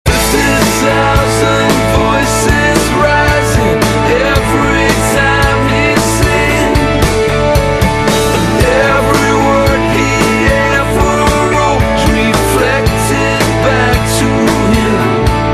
M4R铃声, MP3铃声, 欧美歌曲 78 首发日期：2018-05-14 10:14 星期一